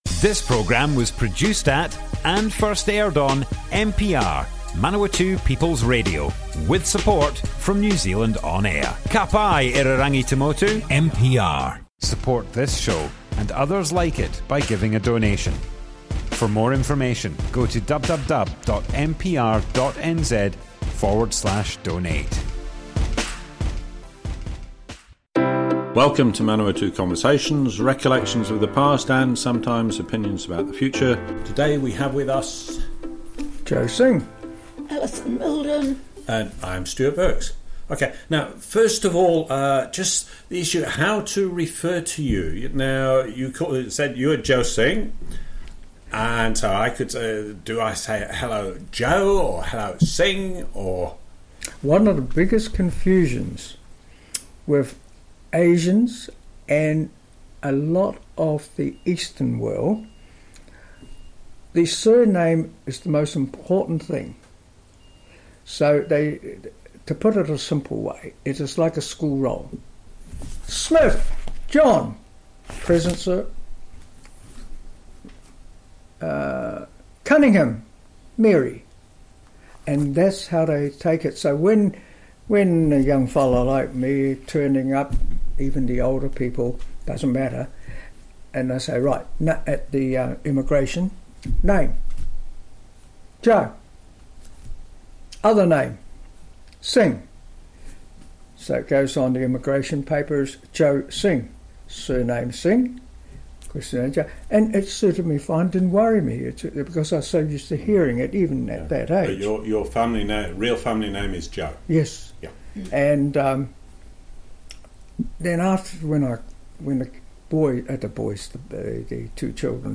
Manawatu Conversations More Info → Description Broadcast on Manawatu People's Radio, 2nd March 2021.
oral history